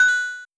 mariopaint_star.wav